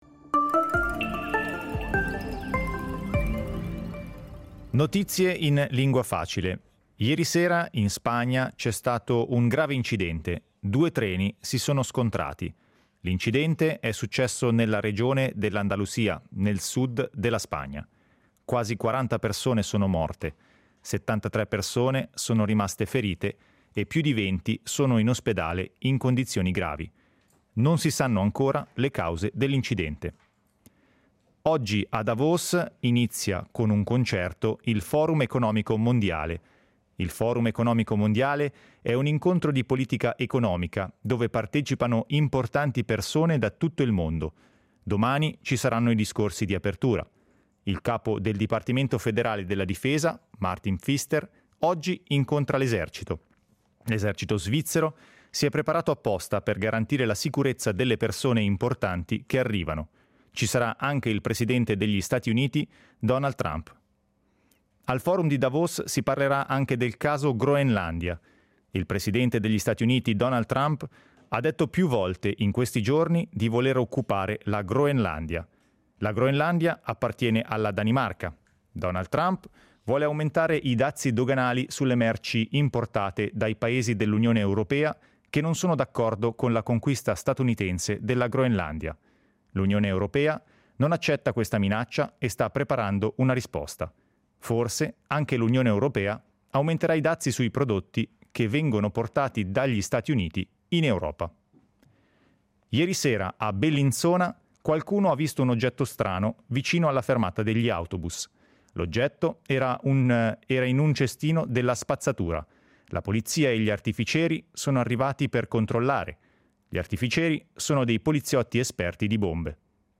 Notizie in lingua facile